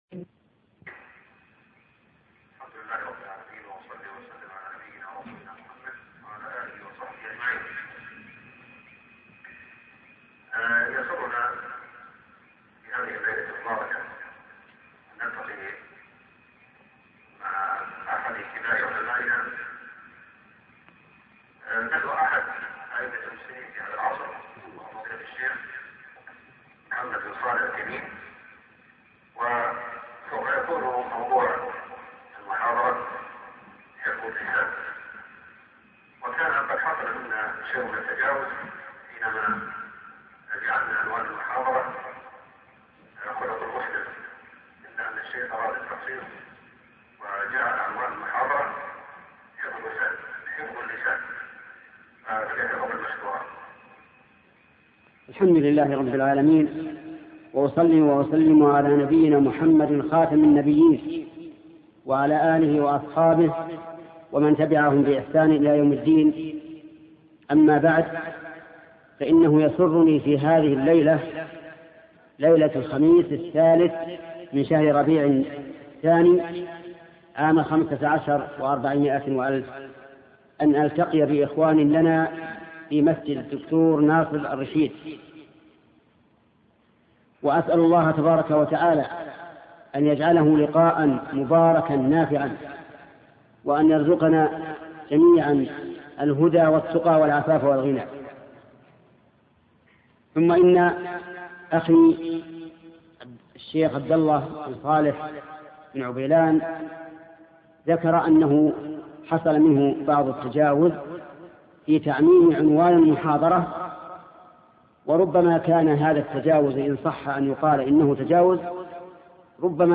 شبكة المعرفة الإسلامية | الدروس | حفظ اللسان |محمد بن صالح العثيمين حفظ اللسان محمد بن صالح العثيمين  الاشتراك  لدي مشكلة  دخول 27/4/1440 حفظ اللسان محاضرة بعنوان حفظ السلام Loading the player...